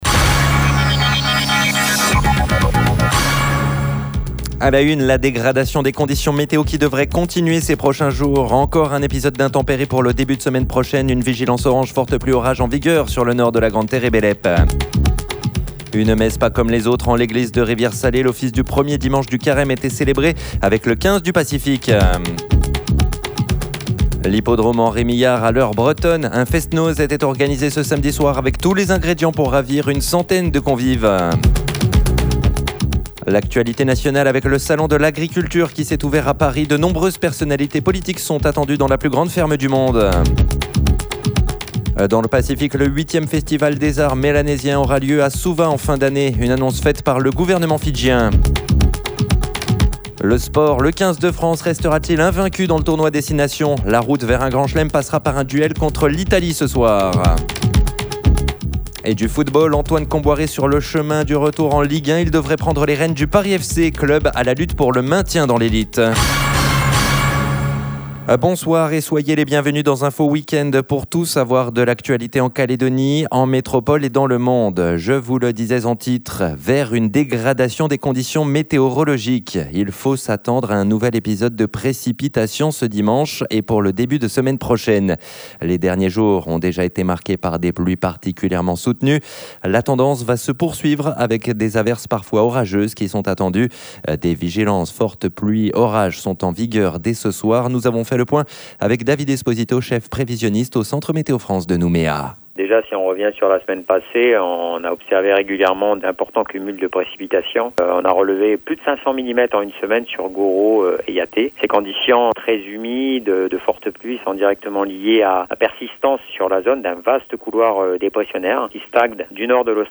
Info-weekend, votre grand journal du weekend, pour tout savoir de l'actualité en Calédonie, en métropole et dans le Monde.